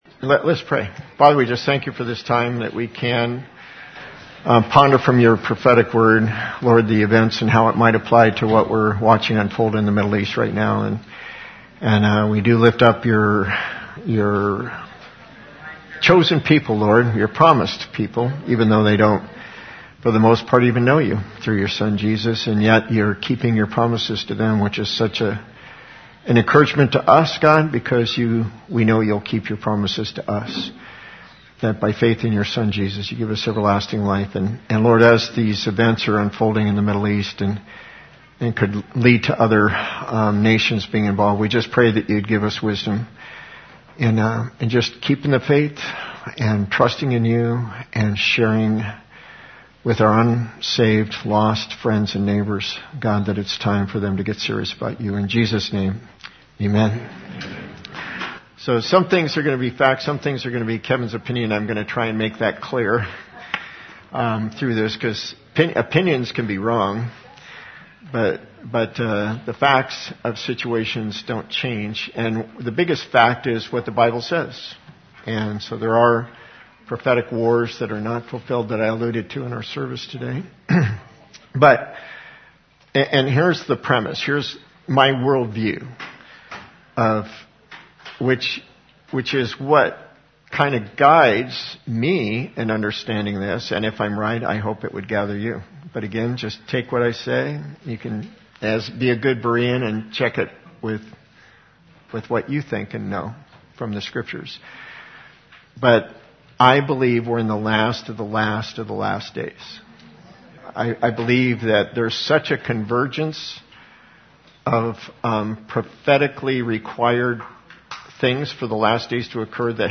Questions and answers from the congregation were also addressed.